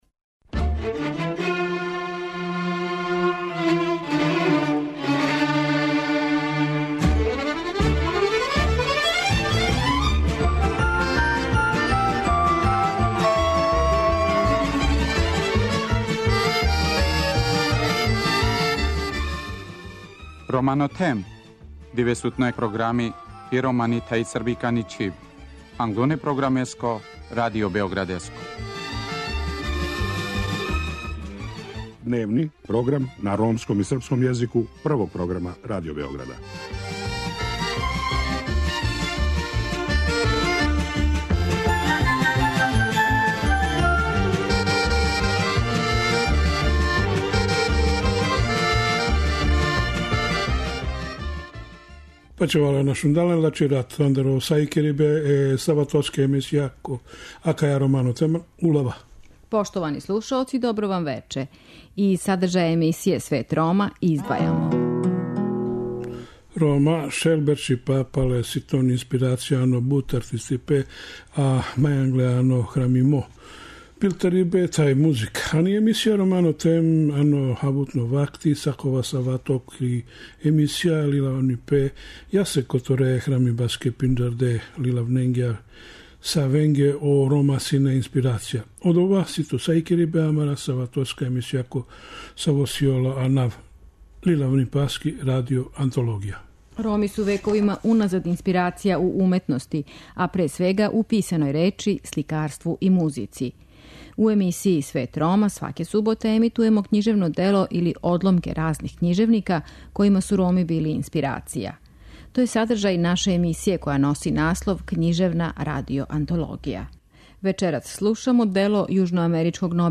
Вечерас слушамо одломак из дела јужноамеричког нобеловца Габријела Гарсије Маркеса "Сто година самоће".